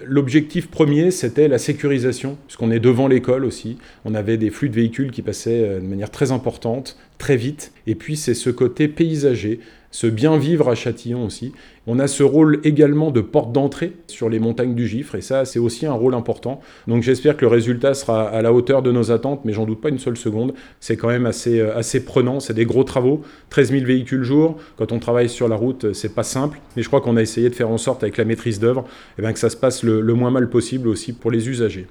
Pendant ce temps les travaux se poursuivent, au moins jusqu’à début 2026, au centre de la commune concernant le réaménagement de la traversée centrale. Cyril Cathelineau rappelle en quoi ils consistent.